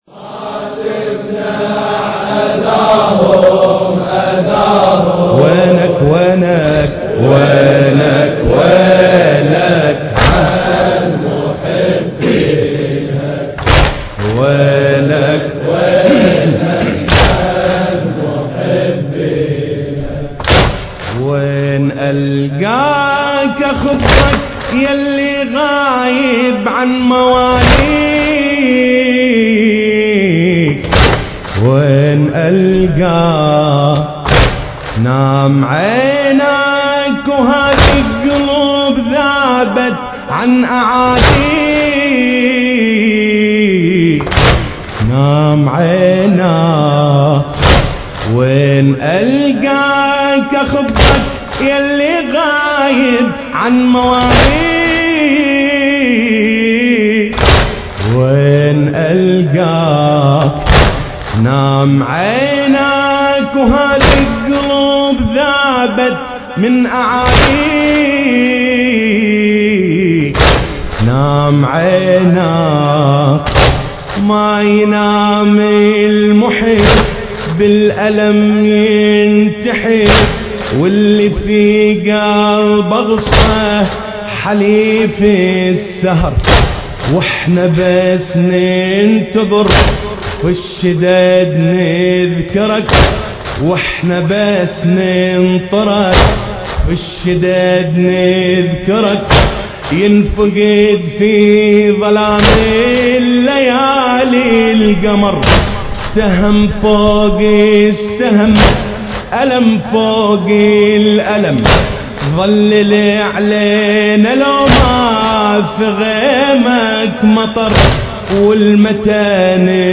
حسينية الولاية ليلة استشهادة الإمام الحسن العسكري (عليه السلام) دولة الكويت
القارئ : حسين الأكرف